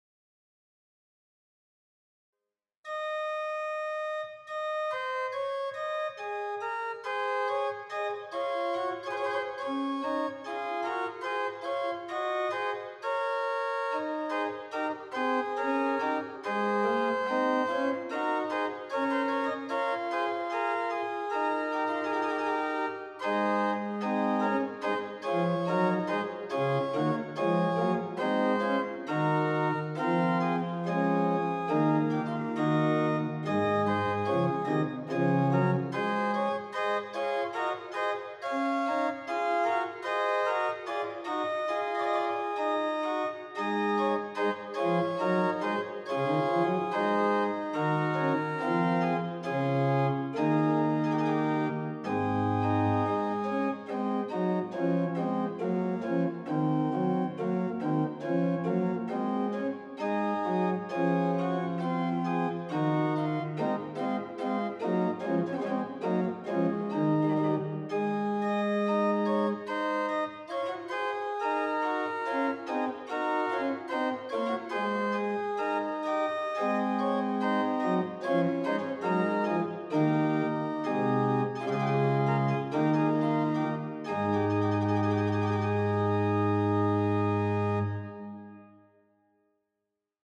Two different (but related) themes each get an exposition and then the fugue is over. Yet it is a pleasant dance like piece, like most of these fugues from Lohet. The recording was done on the sample set of the Silbermann organ of the Stadtkirche Zöblitz by Prospectum.